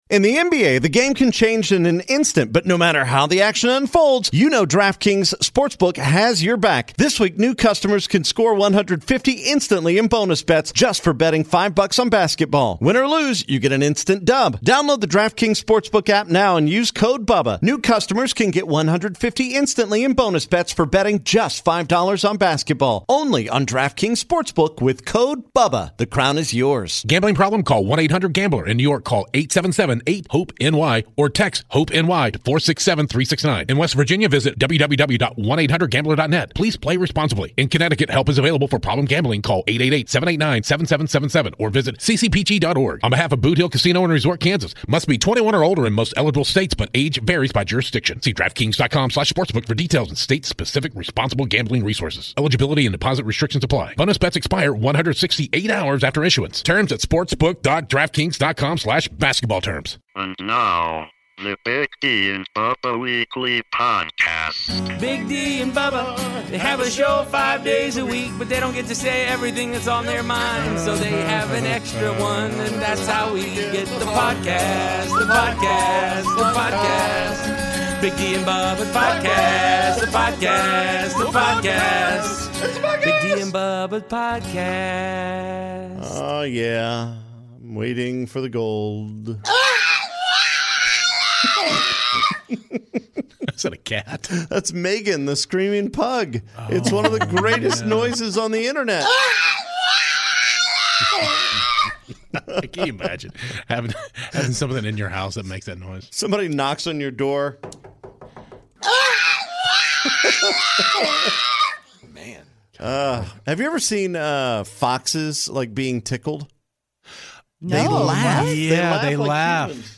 Plus, comedian Bill Engvall tells us about his final comedy special.